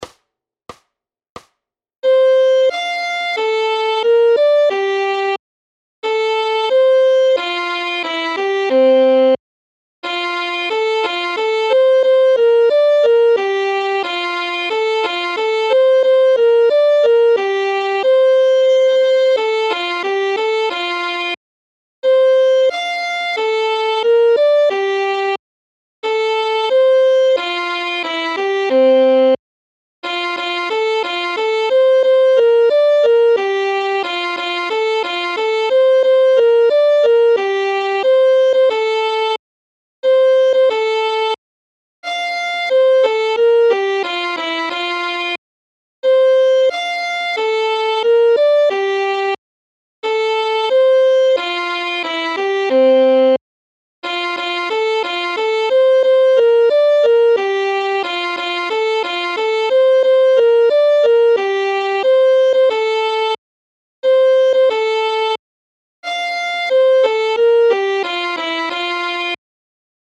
Noty na housle.
Aranžmá Noty na housle
Hudební žánr Vánoční písně, koledy